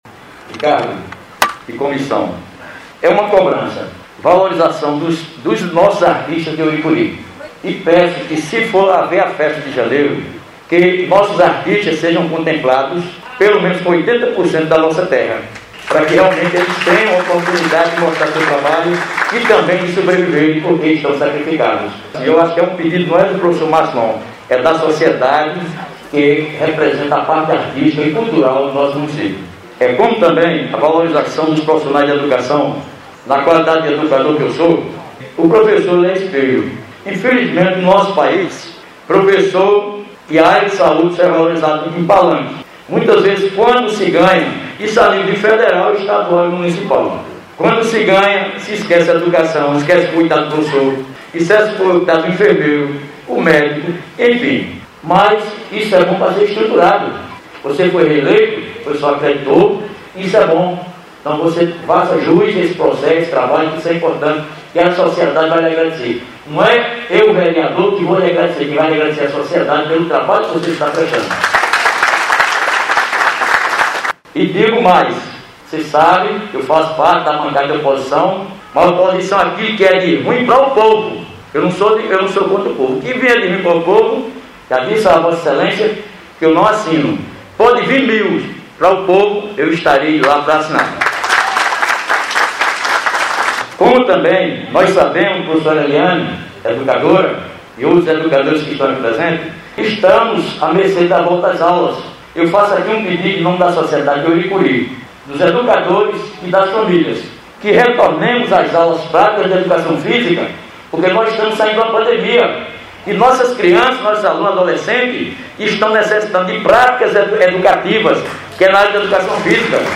O evento ocorreu no Salão Paroquial da Matriz de São Sebastião no centro de Ouricuri.
O vereador Massilon ao fazer uso da palavra fez suas cobranças, ouça.